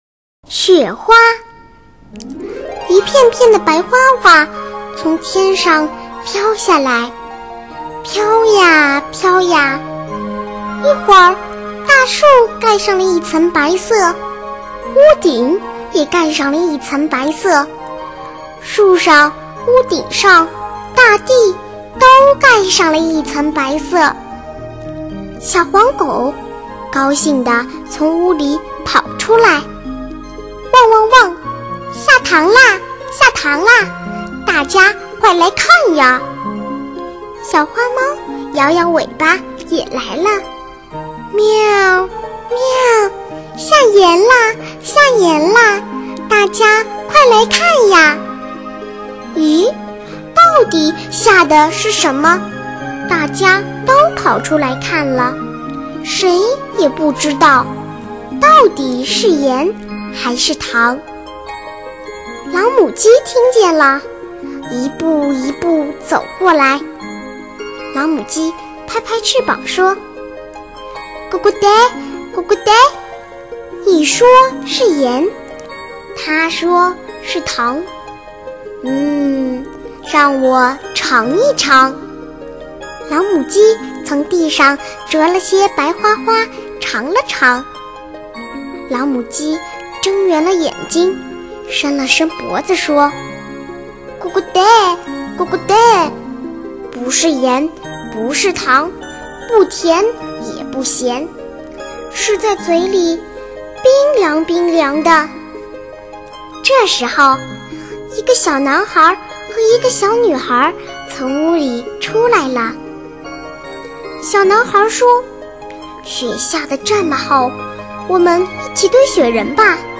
首页>mp3 > 儿童故事 > 雪花